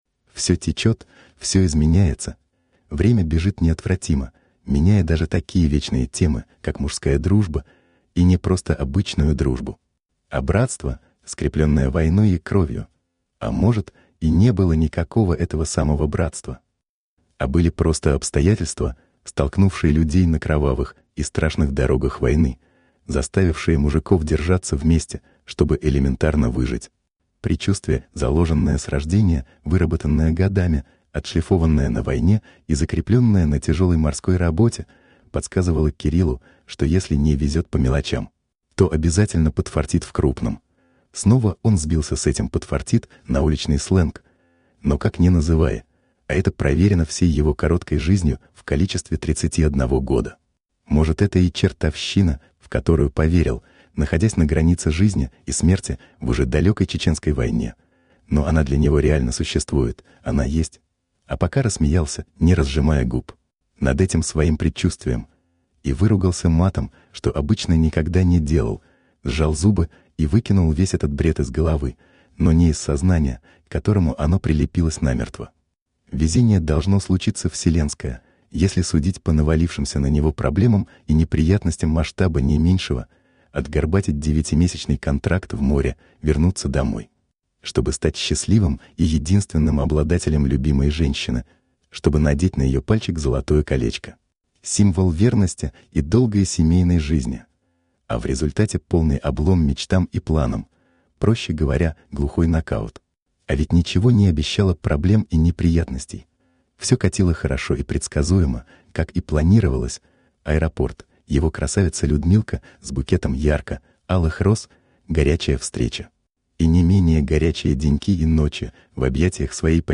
Аудиокнига Однополчане | Библиотека аудиокниг